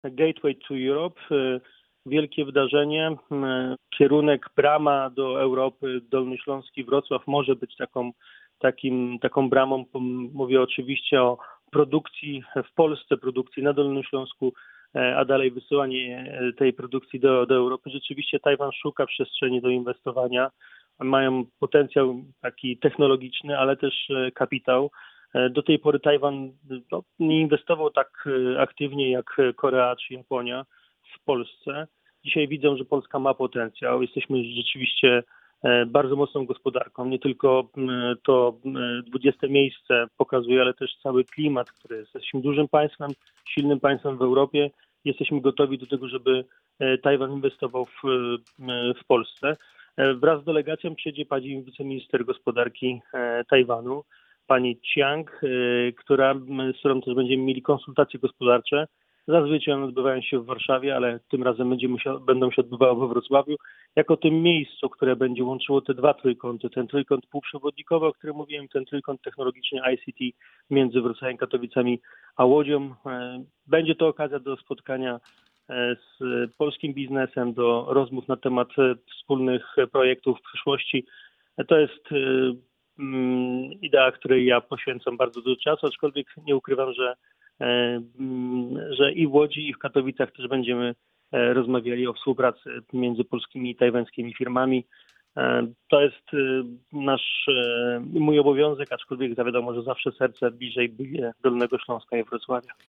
Michał Jaros – wiceminister rozwoju i technologii, szef dolnośląskich struktur KO był dziś naszym „Porannym Gościem”.